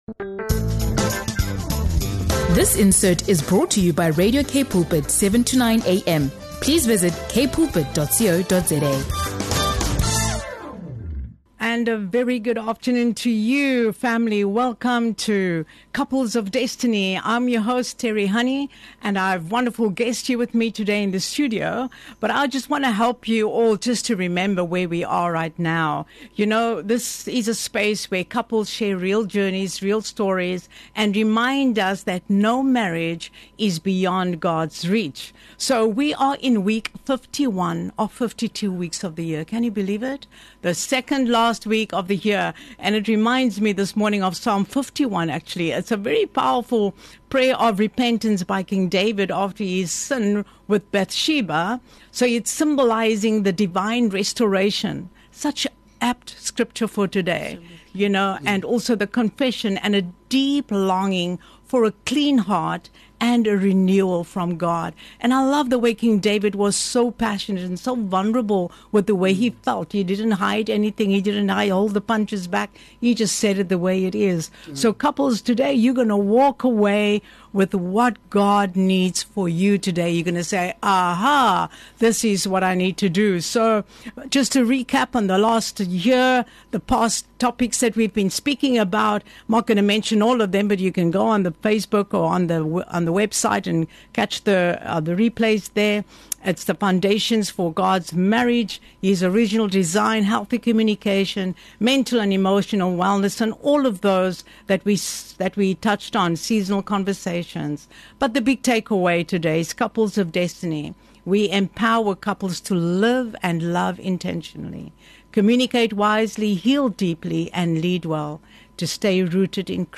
This episode is a heartfelt conversation about marriage restoration, commitment, faith, and love that endures through every season.